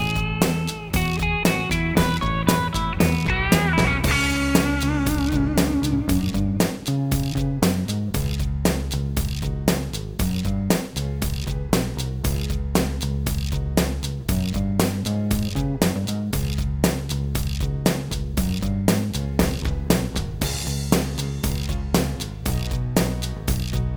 Minus Lead Guitar For Guitarists 4:43 Buy £1.50